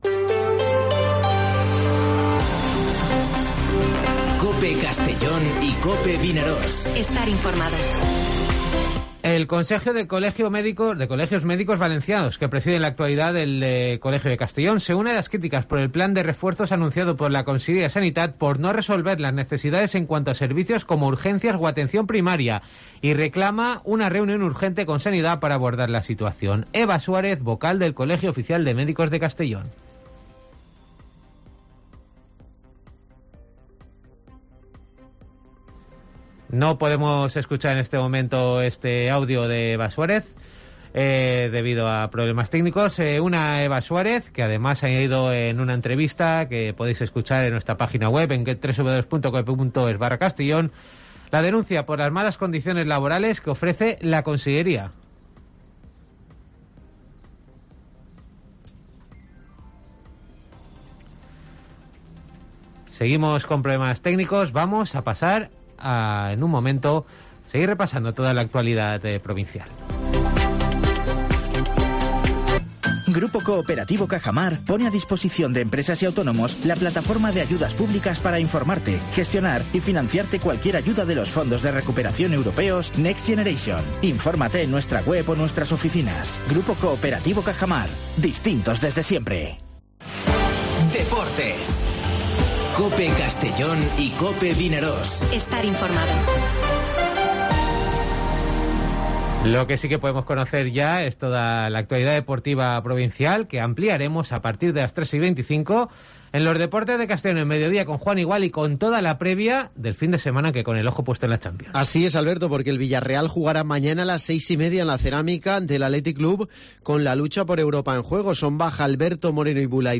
Informativo Mediodía COPE en Castellón (08/04/2022)